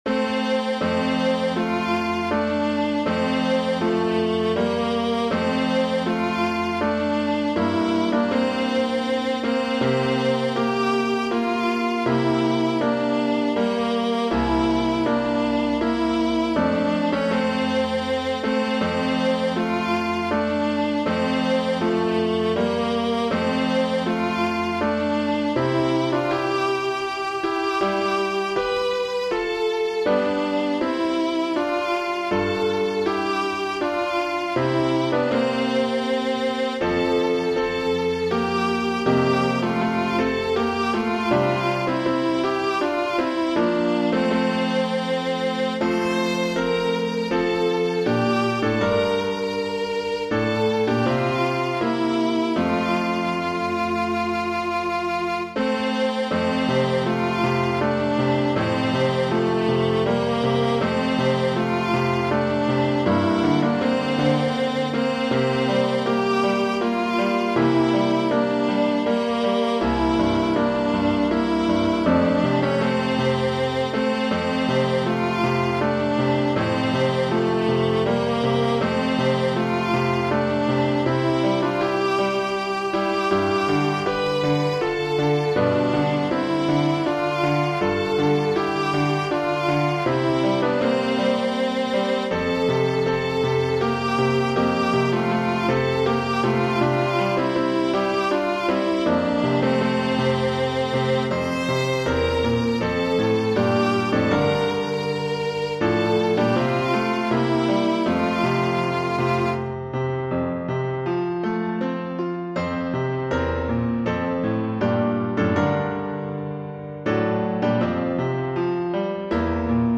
Voicing/Instrumentation: Choir Unison , Vocal Solo